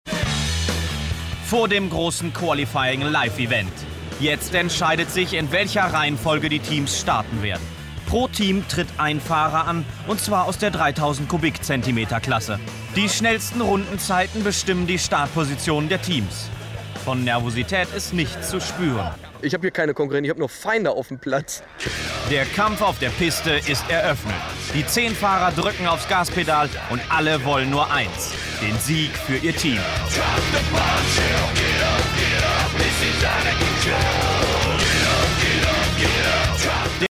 • Sprechproben